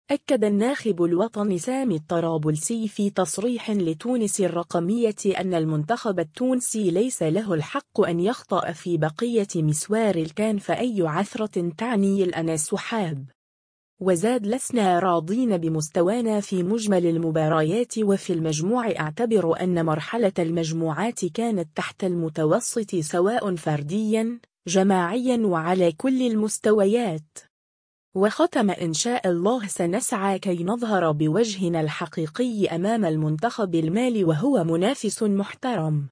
أكّد الناخب الوطني سامي الطرابلسي في تصريح لتونس الرقمية أنّ المنتخب التونسي ليس له الحق أن يخطأ في بقية مسوار “الكان” فأي عثرة تعني الإنسحاب.